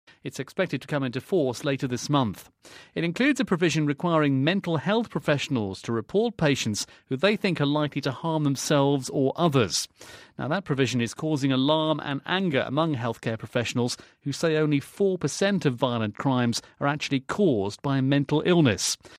【英音模仿秀】美最严枪管法 精神病人“躺枪” 听力文件下载—在线英语听力室